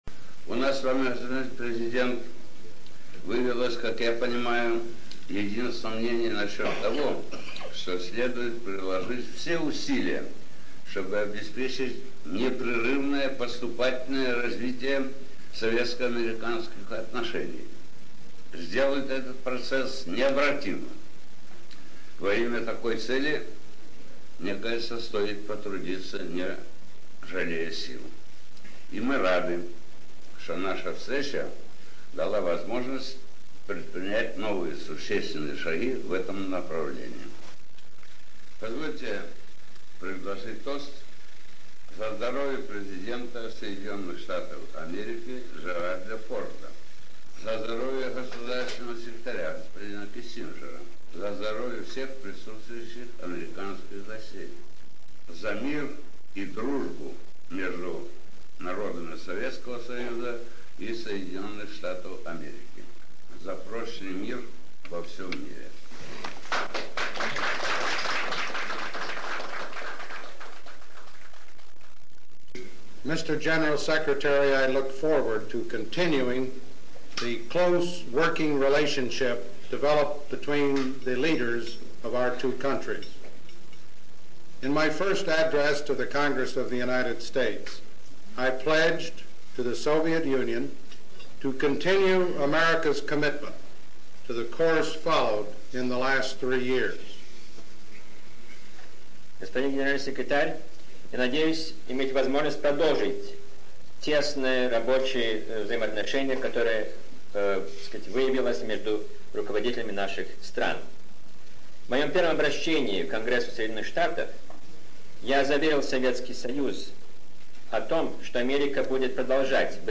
Финальная часть речи Леонида Брежнева и заключительная часть речи Джеральда Форда на ужине во Владивостоке, 24 ноября 1974.